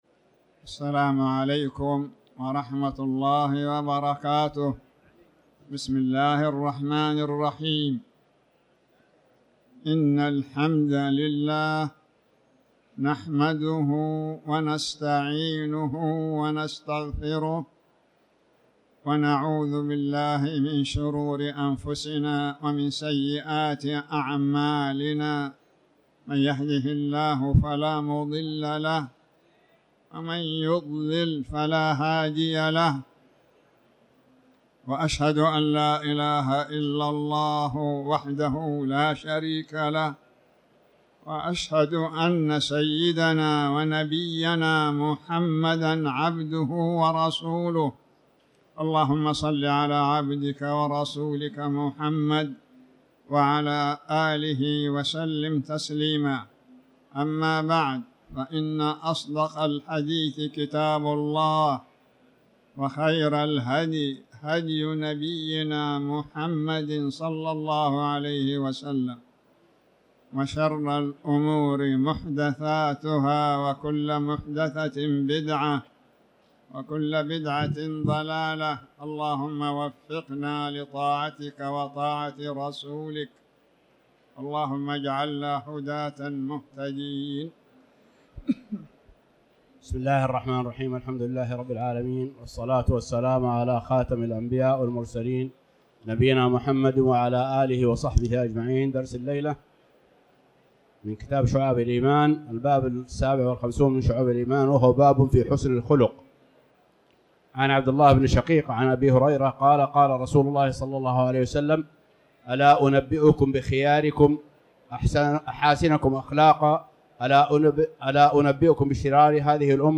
تاريخ النشر ٥ رجب ١٤٤٠ هـ المكان: المسجد الحرام الشيخ